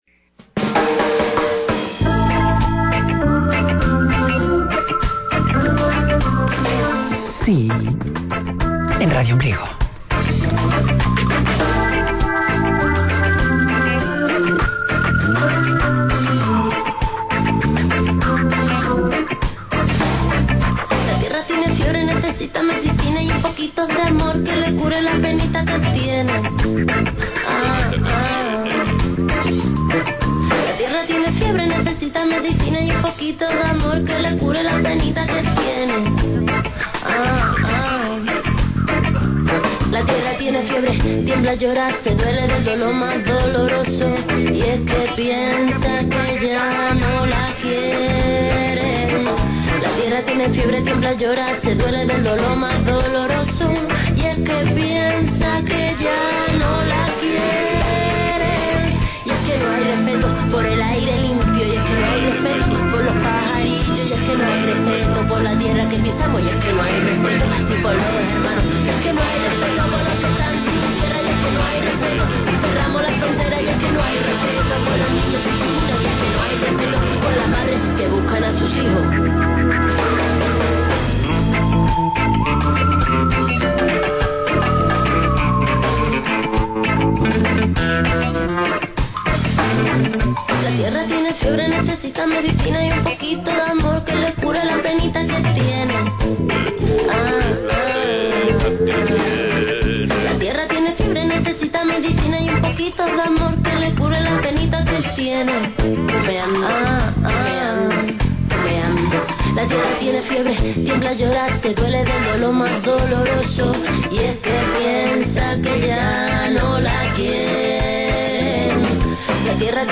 Es un sonido melancólico, triste, suspirante…
También escucharás la voz de Saturno, de Plutón y la del terrible monstruo devorador de todo: el Agujero Negro.